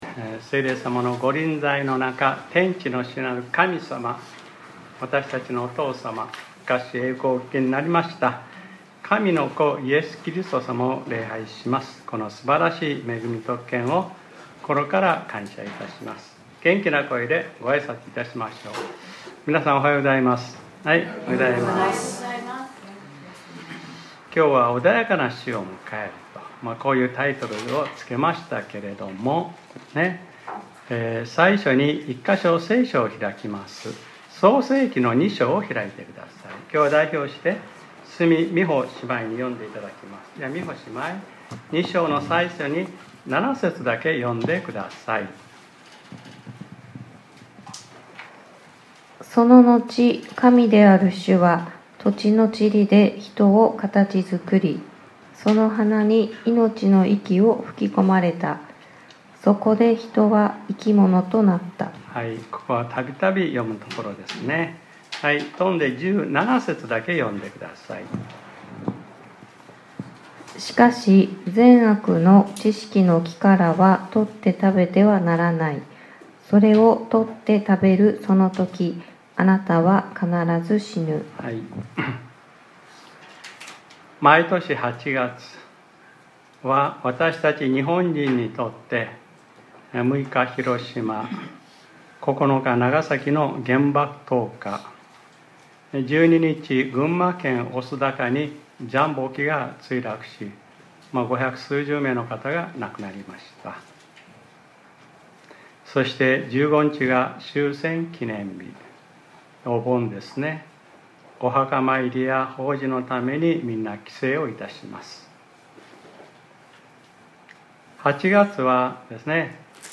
2025年08月10日（日）礼拝説教『 穏やかな死を迎える 』